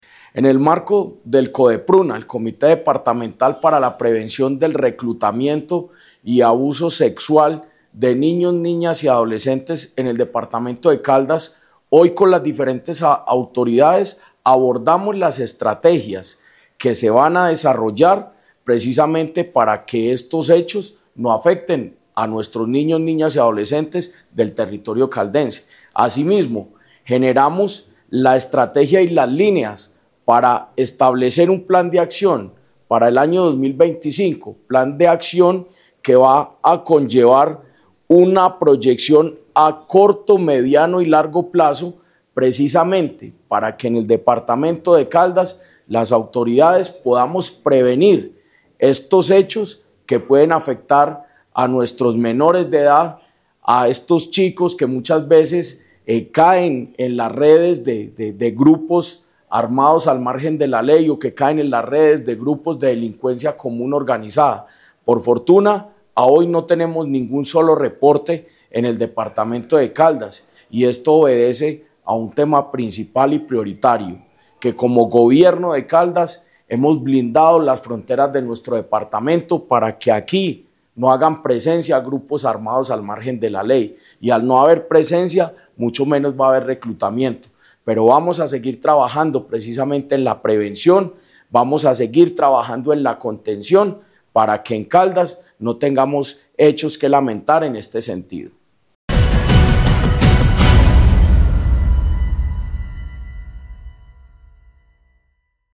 Jorge Andrés Gómez Escudero, secretario de Gobierno de Caldas